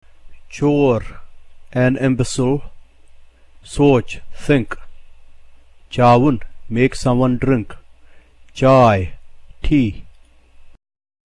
The consonants  has the sound equivalent to that of the combination TS in the English word HUTS.